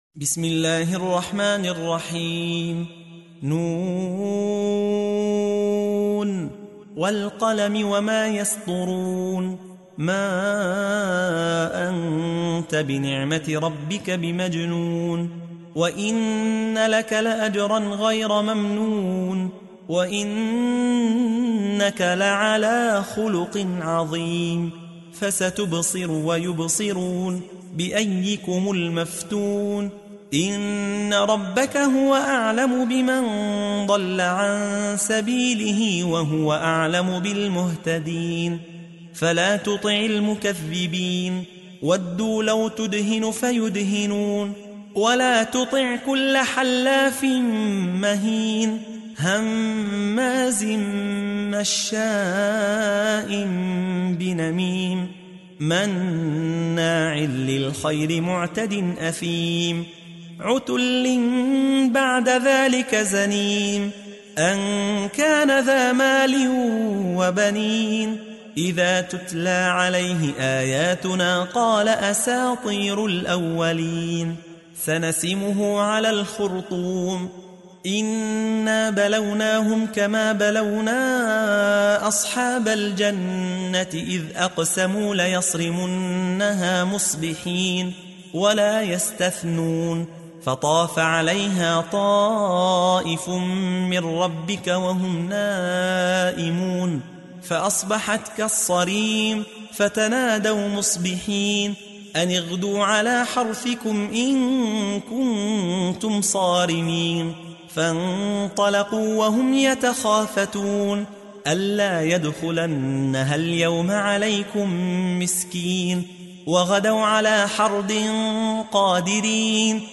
تحميل : 68. سورة القلم / القارئ يحيى حوا / القرآن الكريم / موقع يا حسين